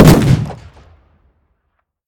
shotgun-shot-4.ogg